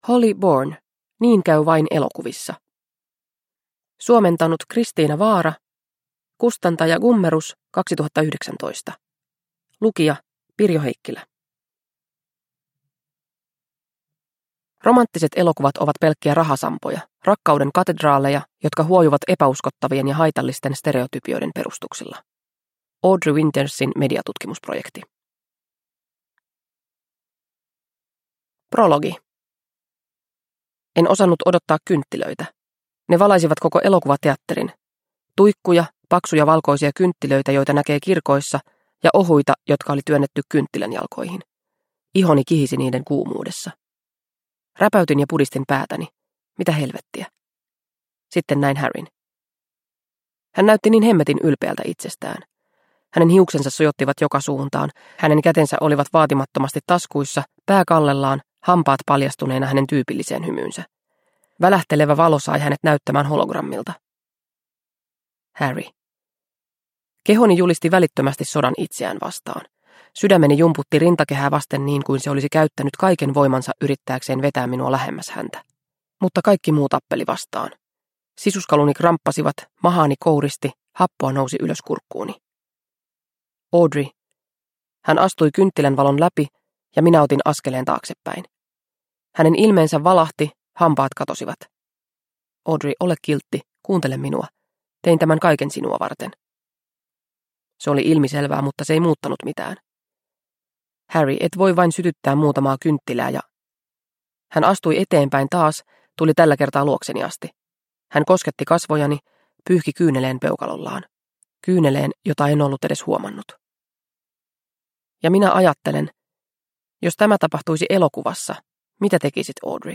Niin käy vain elokuvissa – Ljudbok – Laddas ner